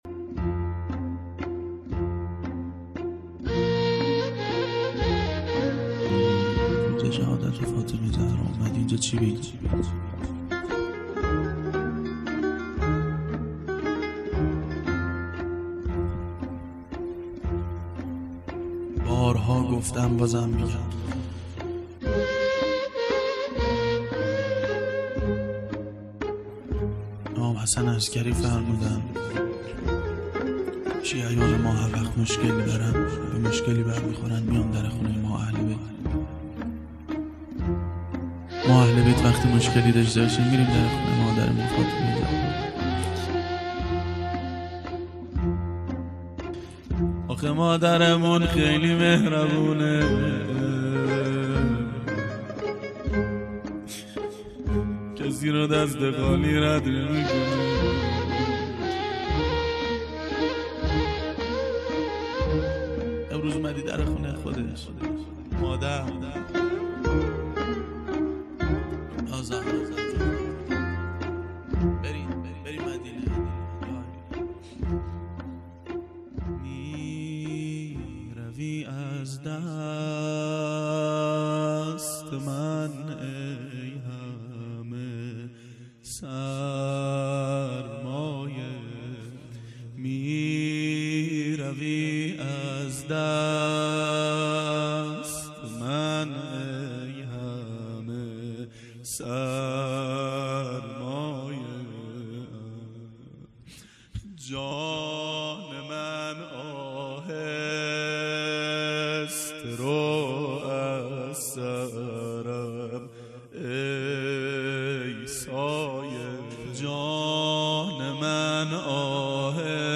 نواهنگ بسیار زیبا-تیزر